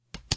洗牌声音
描述：洗牌声音
Tag: 卡洗牌 拖曳 扑克